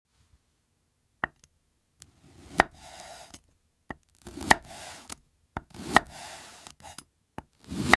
Ever seen a car key sound effects free download